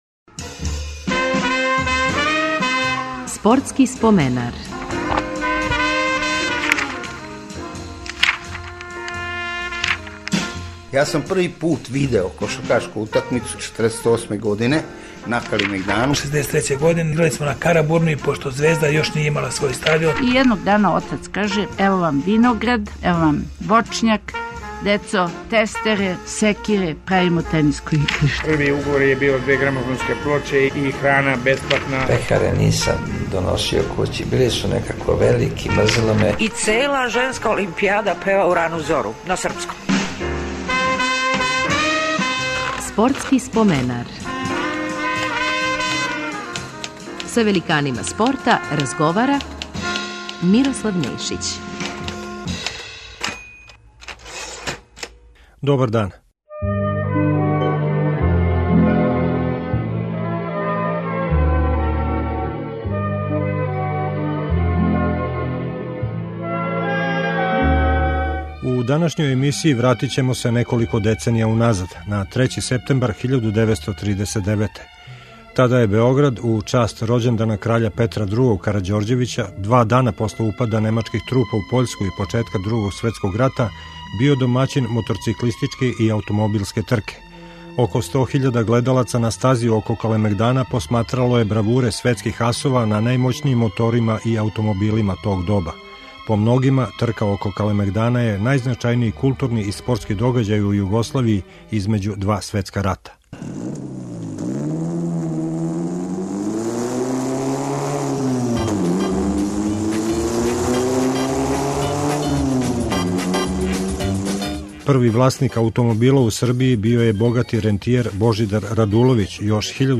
Гости ће бити велики познаваоци тог догађаја